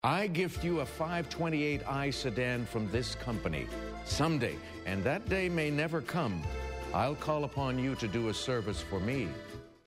(Bill Clinton delivers the clue.)